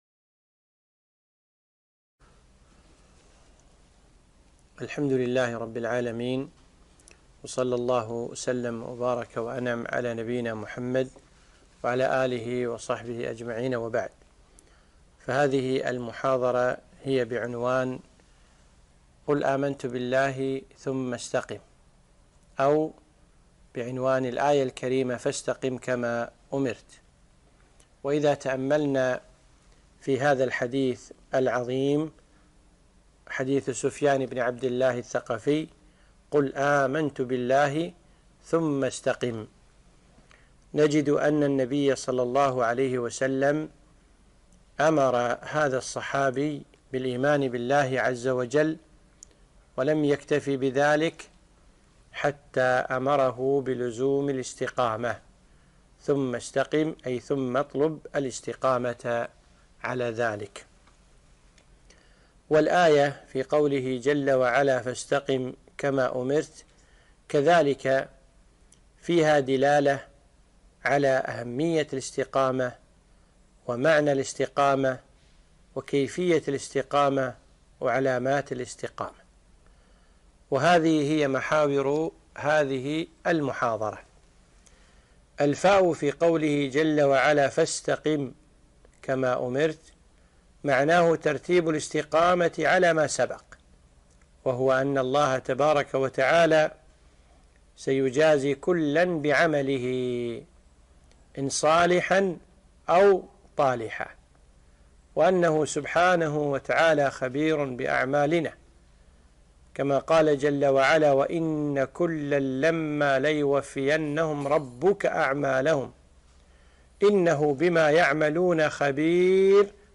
محاضرة - قُلْ آمَنْت بِاَللَّهِ ثُمَّ اسْتَقِمْ